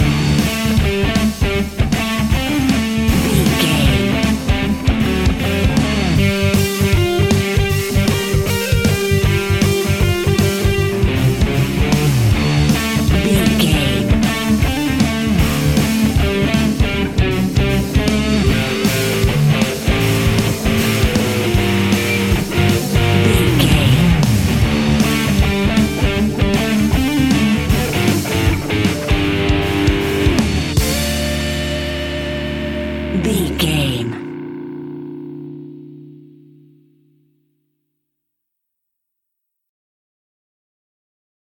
Epic / Action
Aeolian/Minor
D
hard rock
blues rock
distortion
rock instrumentals
rock guitars
Rock Bass
heavy drums
distorted guitars
hammond organ